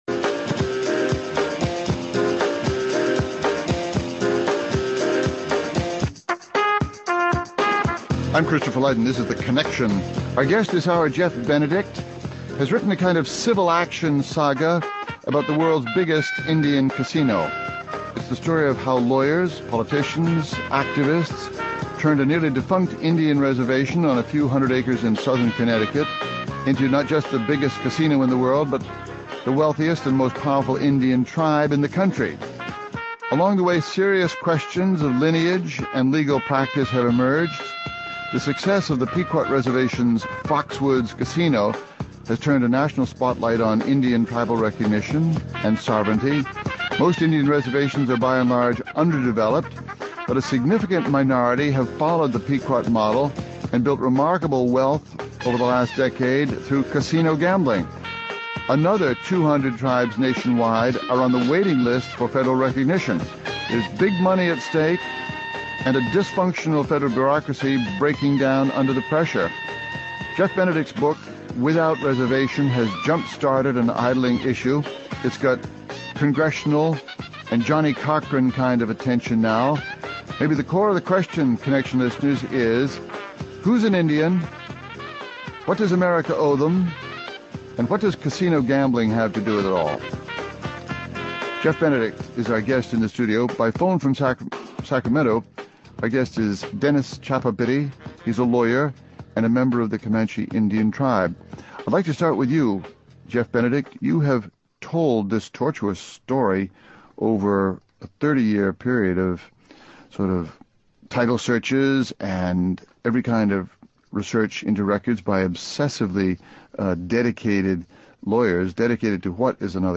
The question is: who’s an Indian, and what does America owe them? (Hosted by Christopher Lydon)